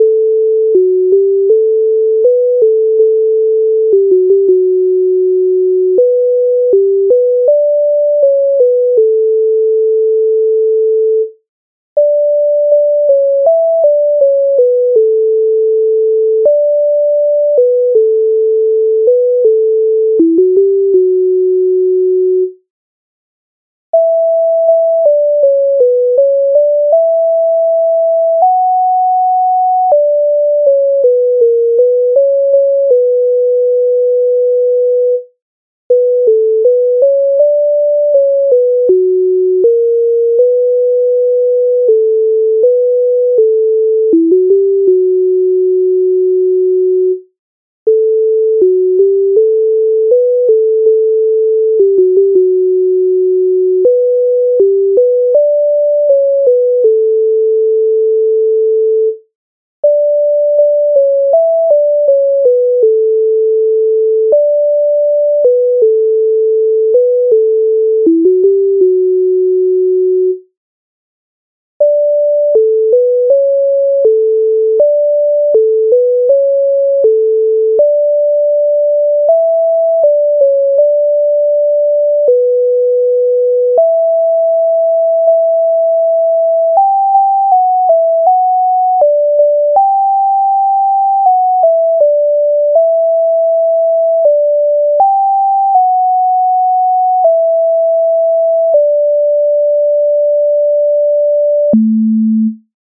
MIDI файл завантажено в тональності D-dur